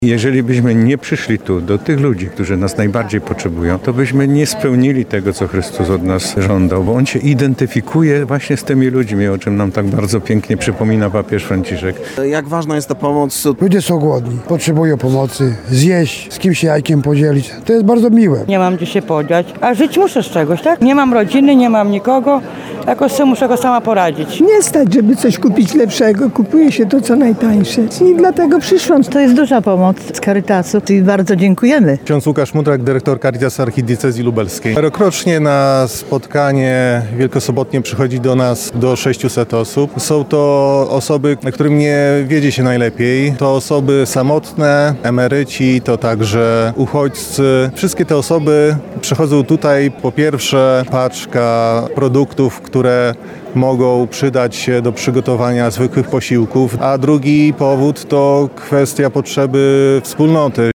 OBRAZEK Caritas - śniadanie wielkanocne dla potrzebujących